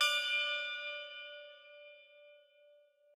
bell1_11.ogg